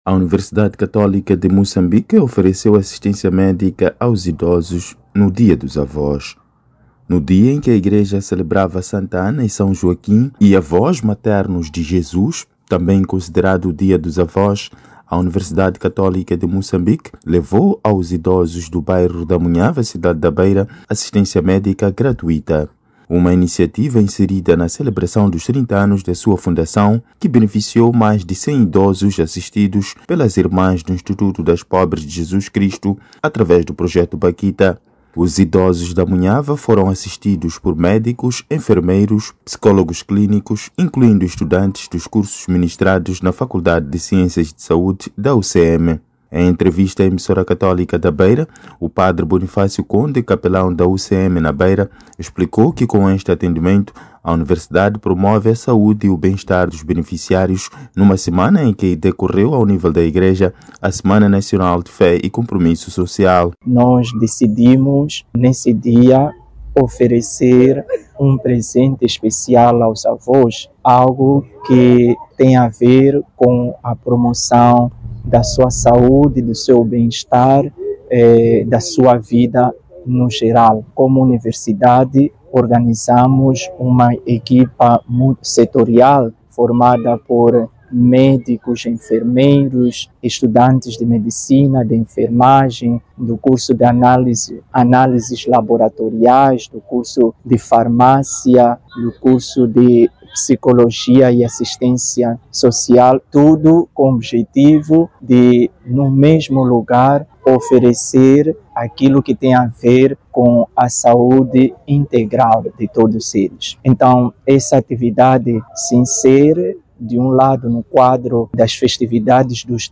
Falando no final da actividade, parte dos voluntários mostraram-se satisfeitos por terem se colocado ao serviço da comunidade, especialmente dos idosos.